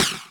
karateman_throw.wav